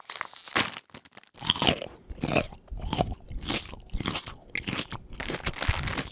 action_eat_chips_2.ogg